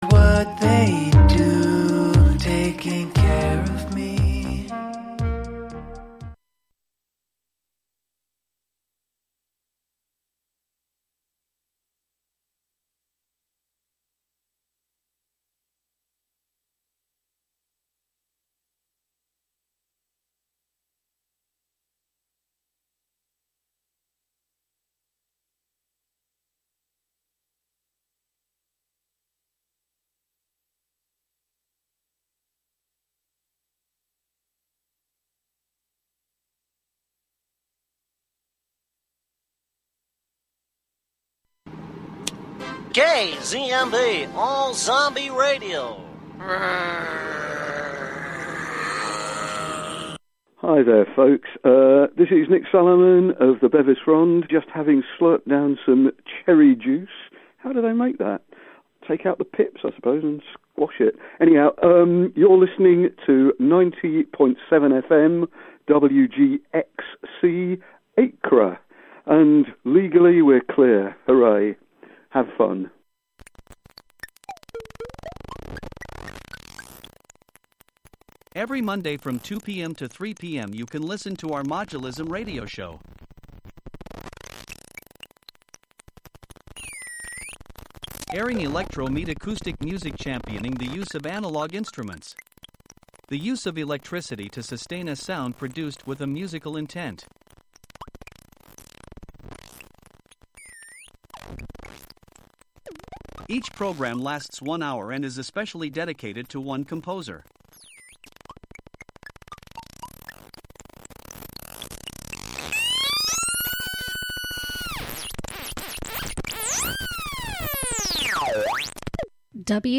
short stories and fables from around the world that are available in print will be the only topic here. i won’t offer commentary or biographies on-air. just the fictions read for the listener as best as i can read them.